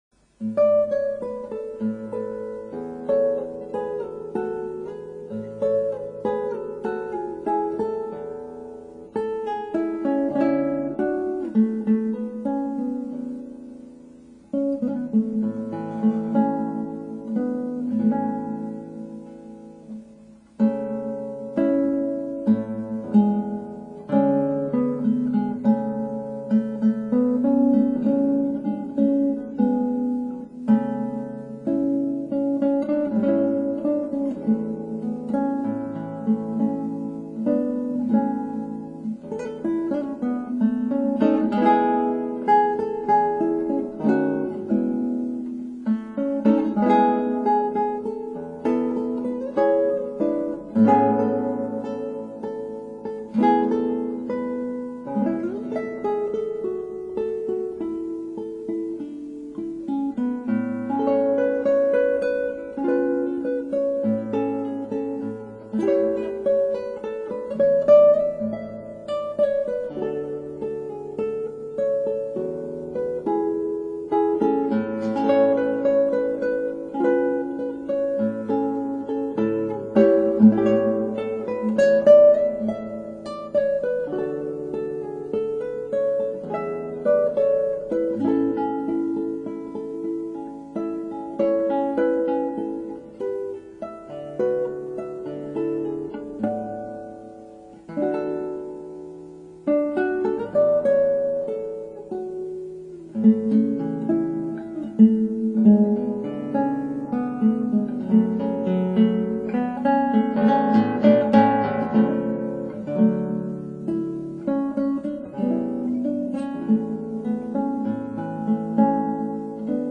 たしかマイクは会議用のステレオコンデンサマイクでした。
このエコーノイズひどかったんだよね。
録音手順は、まずMDで録音して、それをDAW(CakeWalk)を使ってPCに取り込んでいたのです。
さて、やっぱり音悪いですねぇ。なんというか・・・解像度が悪い、ボケた音ですね。
ノイズだらけだし。